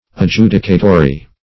adjudicatory.mp3